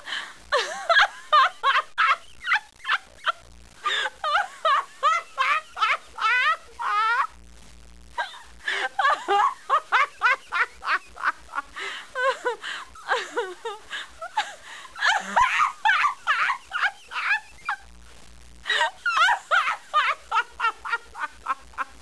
女人疯笑声音效免费音频素材下载